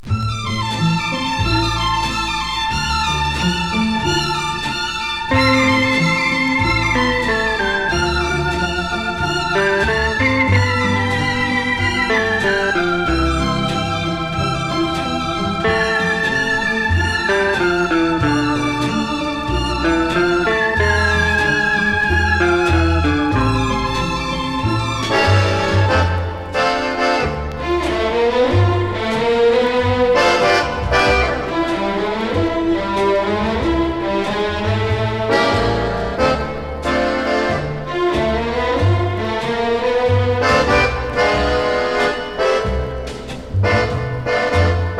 曲ごとの楽器選択が楽しく、演出も有り色取り取りのサウンドで新鮮さを感じます。
Jazz, Easy Listening, Mood　USA　12inchレコード　33rpm　Mono